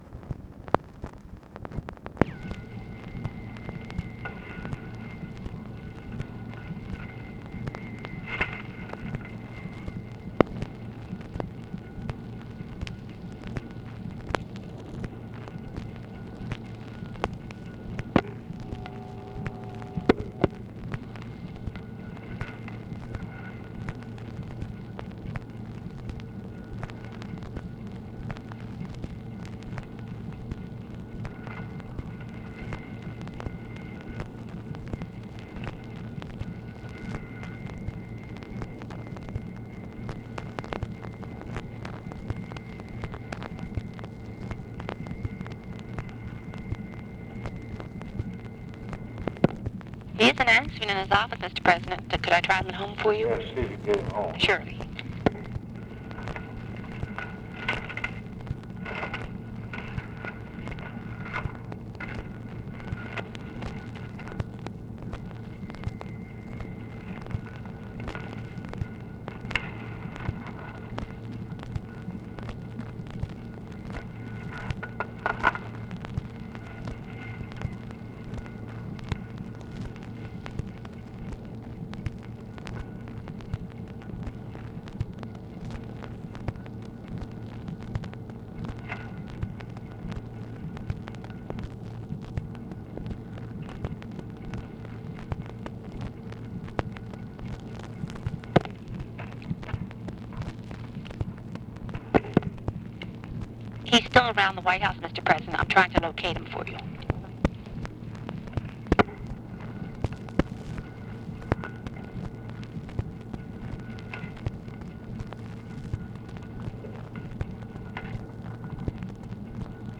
Conversation with MYER FELDMAN, TELEPHONE OPERATOR and OFFICE NOISE, August 11, 1964